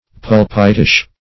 Search Result for " pulpitish" : The Collaborative International Dictionary of English v.0.48: Pulpitish \Pul"pit*ish\, a. Of or pertaining to the pulpit; like preaching.
pulpitish.mp3